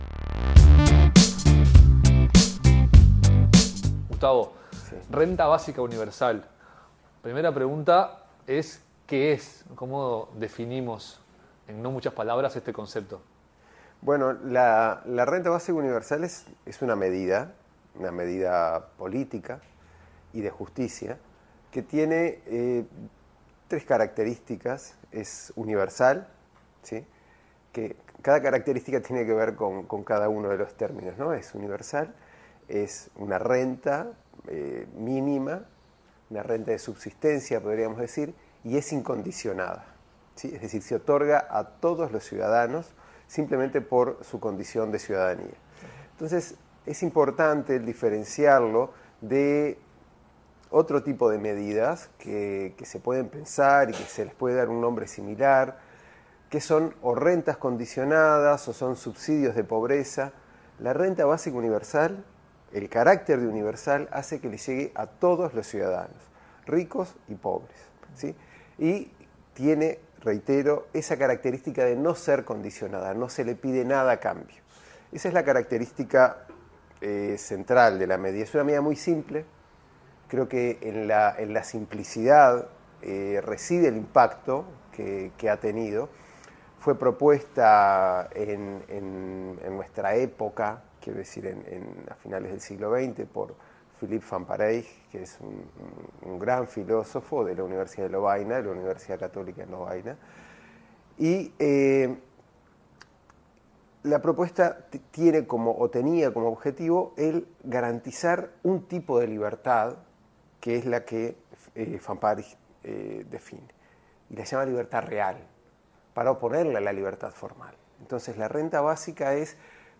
Conversamos con el doctor en Filosofía y docente universitario